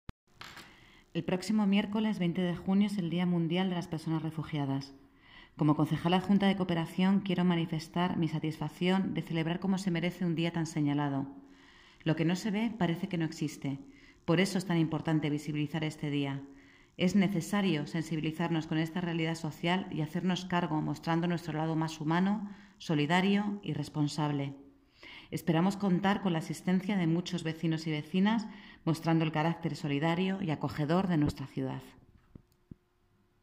Audio - Beatriz Mogrovejo (Concejala Adjunta Cooperación y Convivencia) Sobre Día del Refugiado